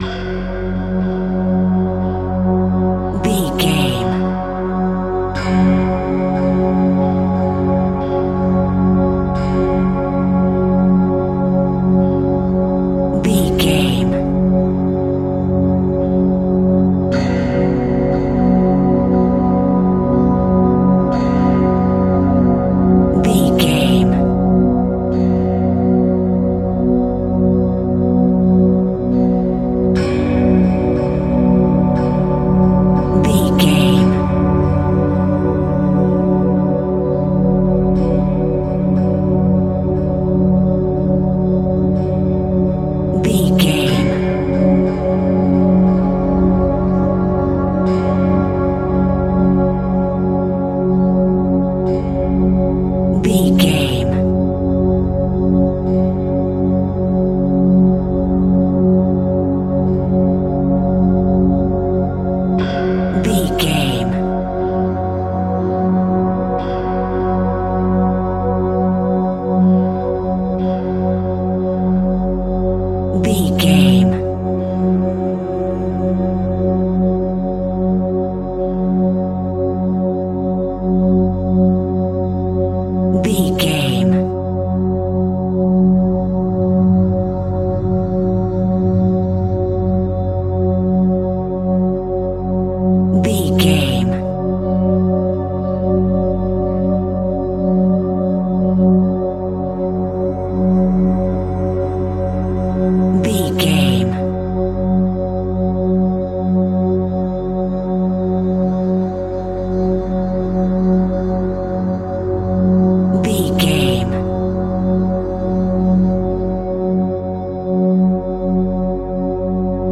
Thriller Atmosphere.
Aeolian/Minor
F#
tension
ominous
dark
eerie
ethereal
synthesiser
Synth Pads
atmospheres